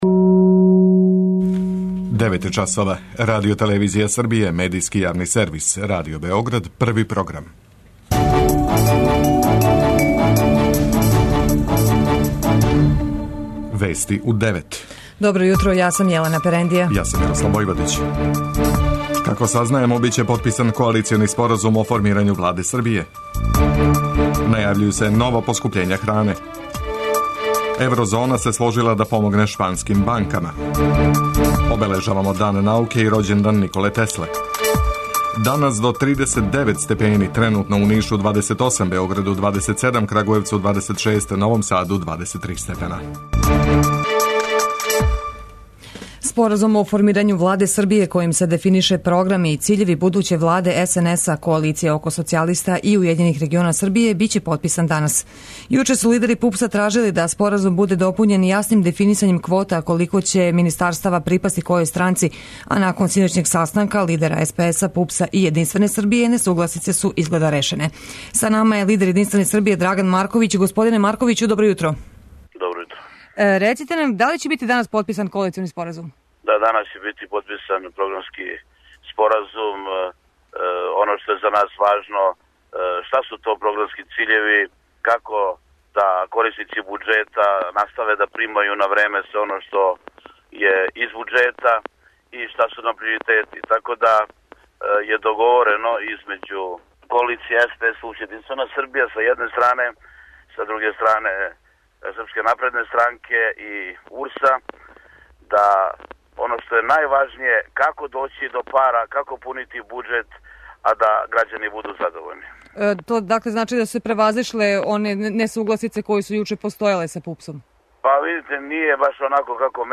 преузми : 9.95 MB Вести у 9 Autor: разни аутори Преглед најважнијиx информација из земље из света.